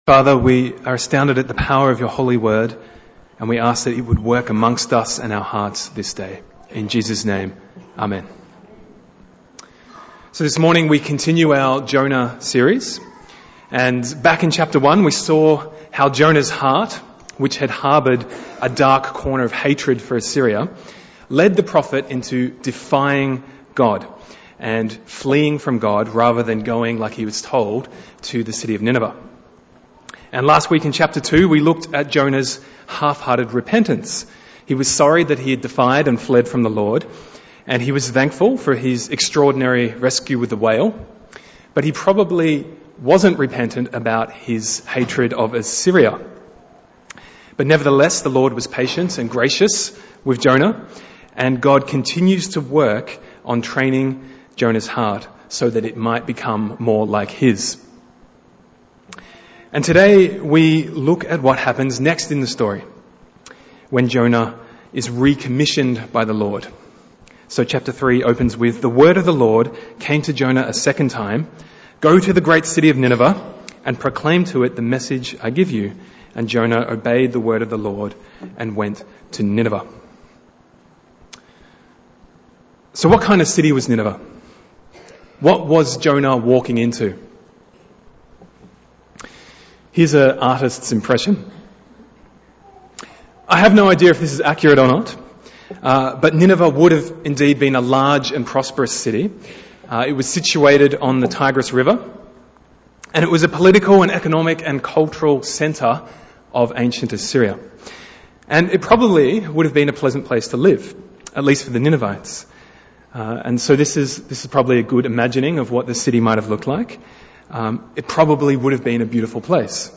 Bible Text: Jonah 3:1-10 | Preacher